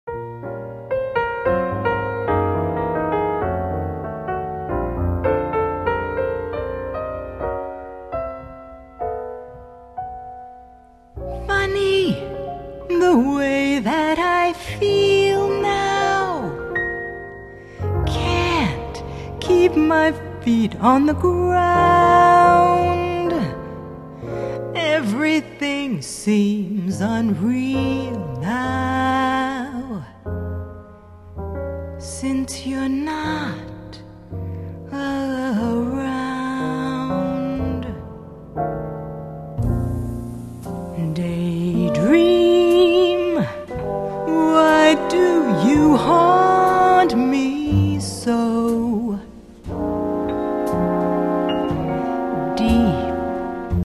Jazz Vocalist
All tracks are short samples.
trombone (1, 4, 5, 8, 12, 13, 15)